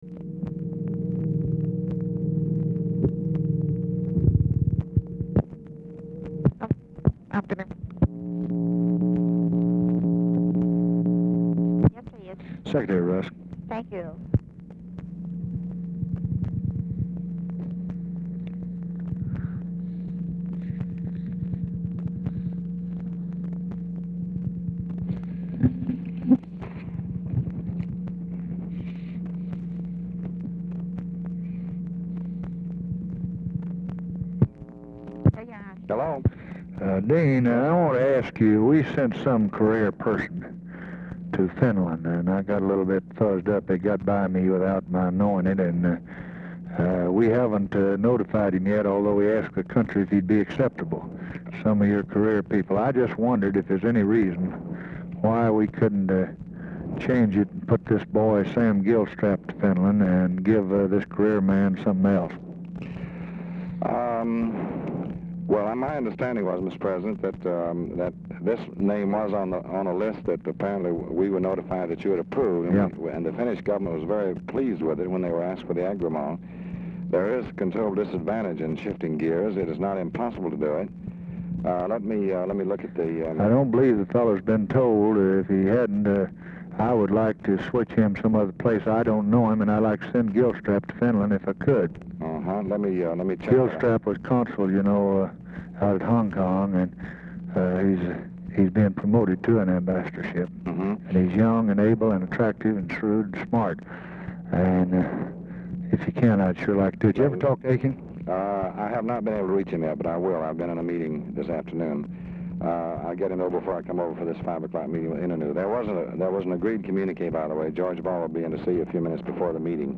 Telephone conversation # 3847, sound recording, LBJ and DEAN RUSK, 6/23/1964, 4:43PM | Discover LBJ
Format Dictation belt
Location Of Speaker 1 Oval Office or unknown location